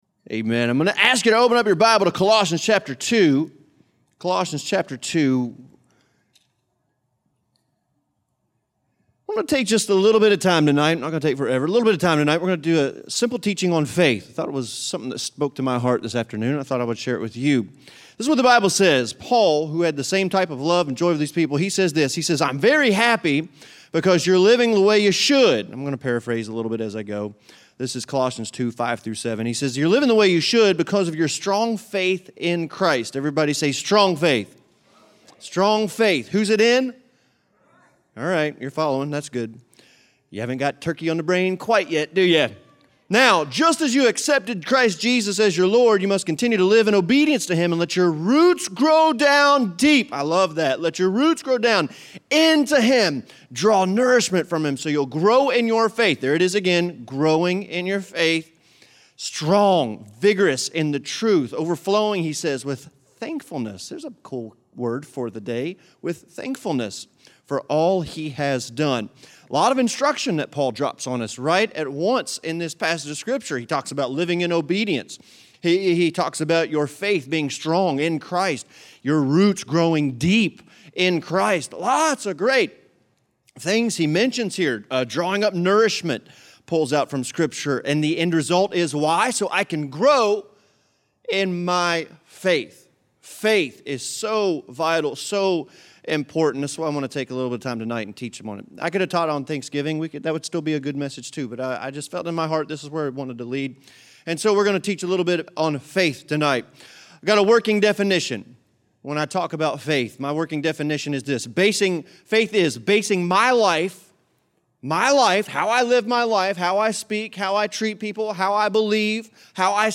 Word of Faith Preaching and Teaching at New Lew Life Church.